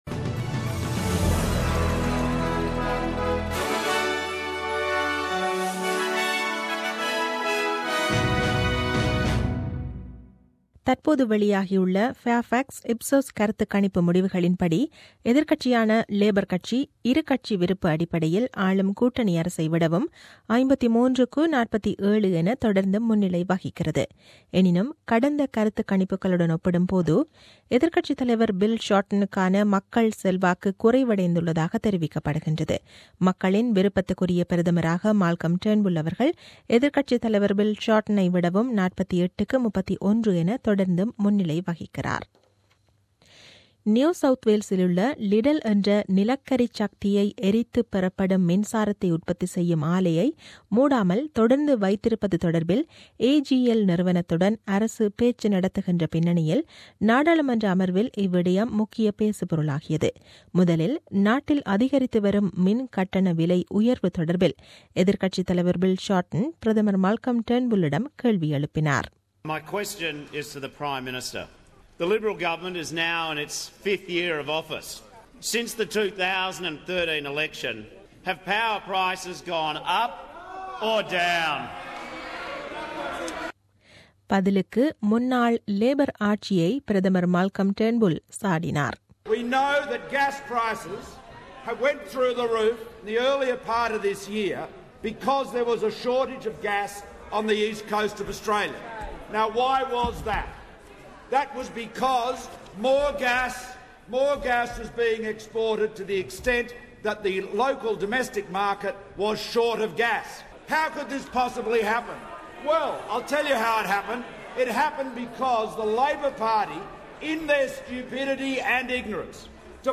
The news bulletin aired on 11 Sep 2017 at 8pm.